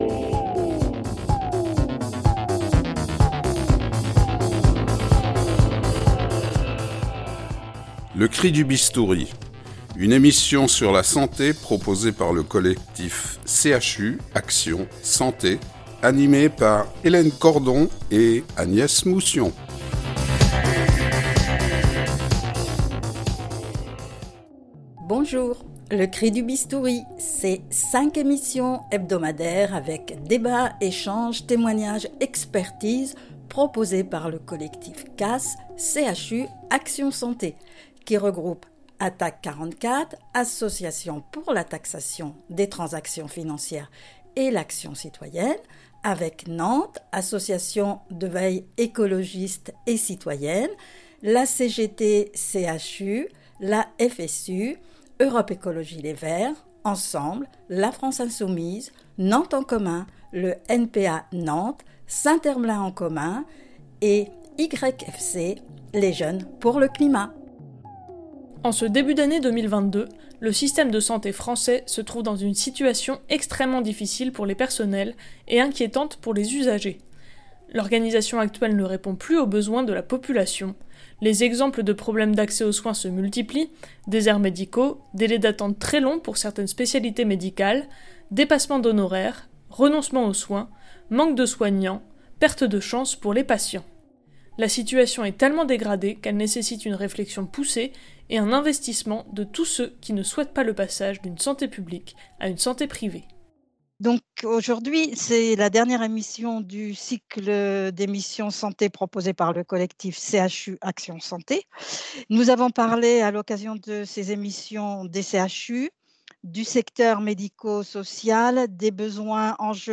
Trois médecins généralistes seront avec nous et présenteront différentes modalités permettant de construire une santé pour toutes et tous près de chez eux dans les conditions d’une démocratie sanitaire en considérant la santé comme un bien commun.